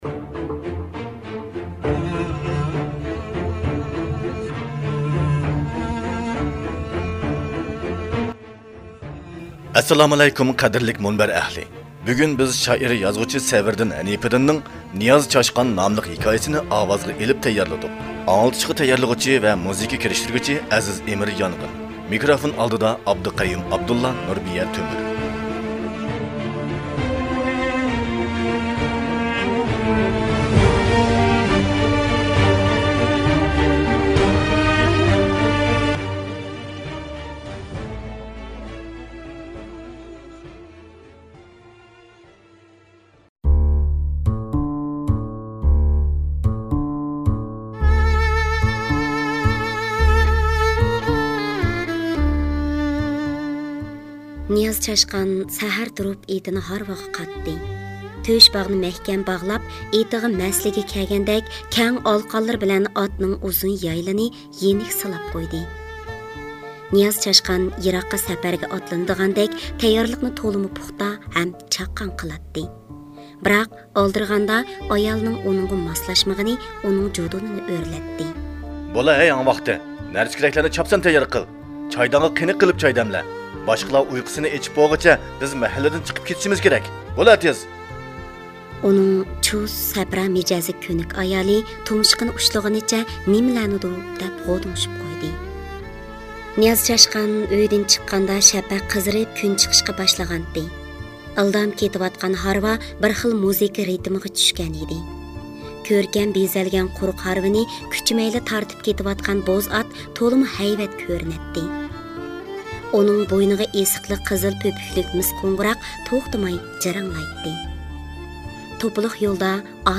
نىياز چاشقان( ئاۋازلىق)
نىياز چاشقان (ھېكايە)